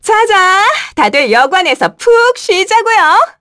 Juno-Vox_Victory_kr.wav